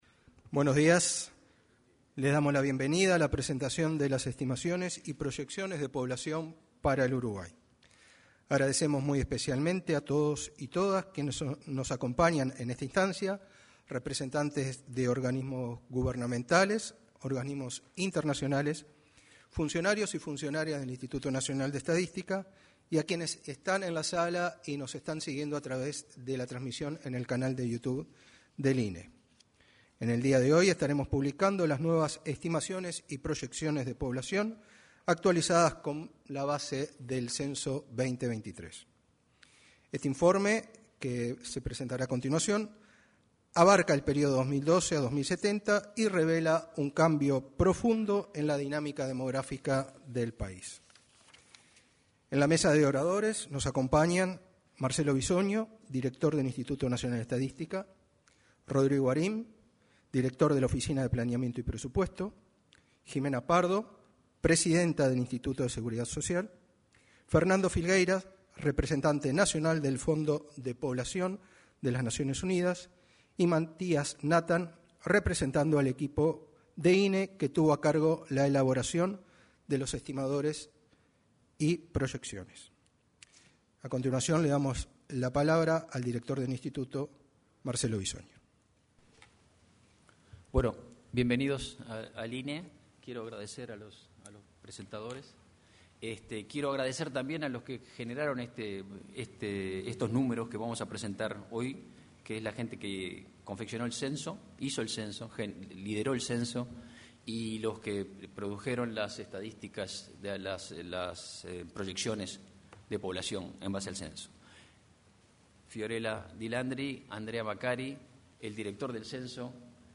Presentación de proyecciones y estimaciones de población a partir de los datos del Censo 2023